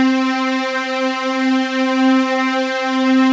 If this does not work, could anyone try whether the following wave file plays without clicks when the whole sample is looped from beginning to end?